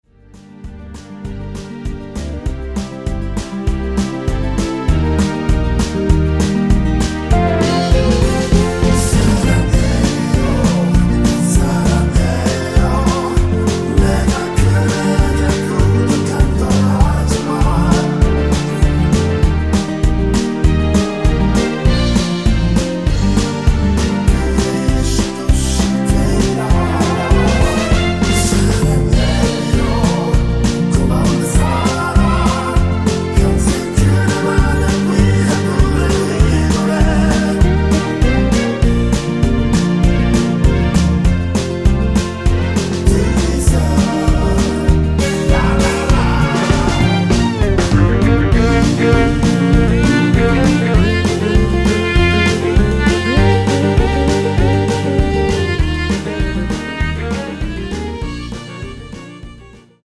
원키에서(-2)내린 코러스포함된MR 입니다.(미리듣기 확인)
Bb
앞부분30초, 뒷부분30초씩 편집해서 올려 드리고 있습니다.
중간에 음이 끈어지고 다시 나오는 이유는